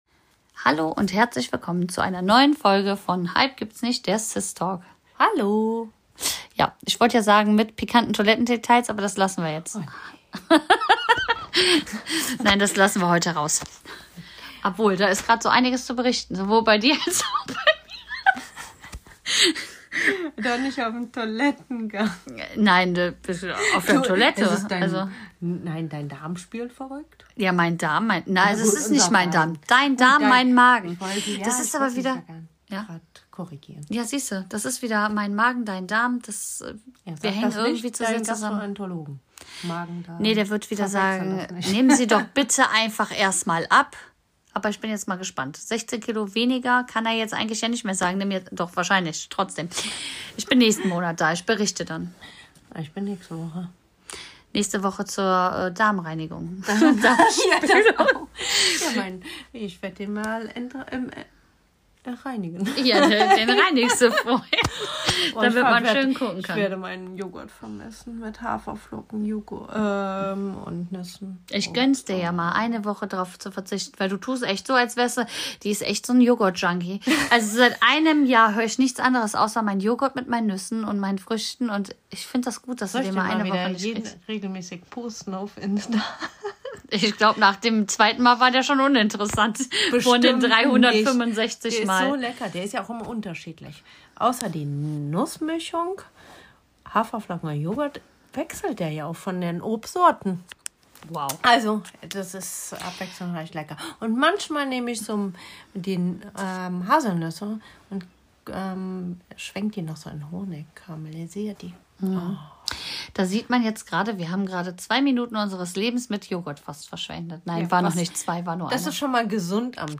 In dieser Podcastfolge unterhalten sich zwei Schwestern über ihr gemeinsames Leben, ihre Beziehung zueinander und die Themen, die sie aktuell beschäftigen.